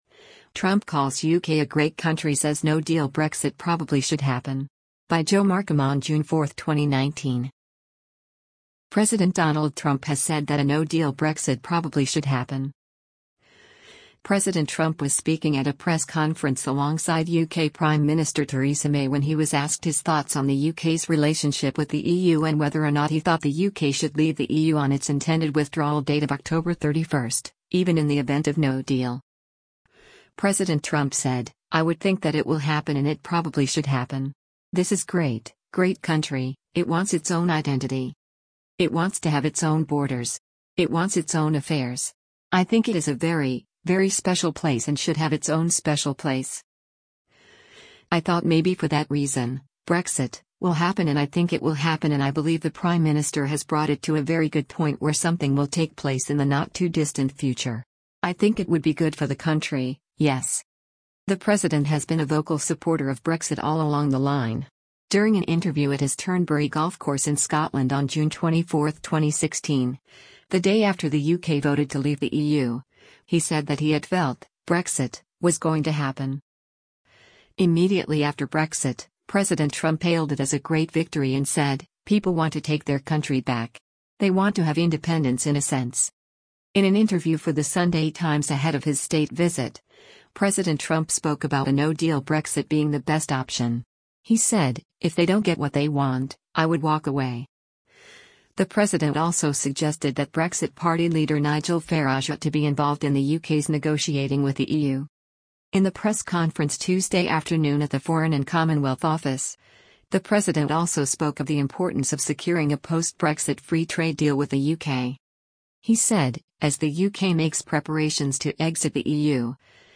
US President Donald Trump speaks during a joint press conference with Britain's Prime Mini
President Trump was speaking at a press conference alongside UK Prime Minister Theresa May when he was asked his thoughts on the UK’s relationship with the EU and whether or not he thought the UK should leave the EU on its intended withdrawal date of October 31st, even in the event of ‘no deal’.